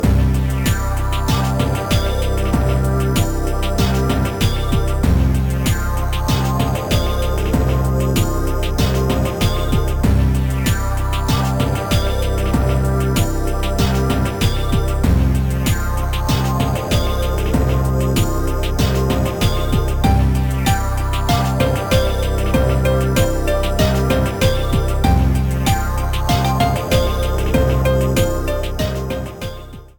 Clipped to 30 seconds and added fade-out.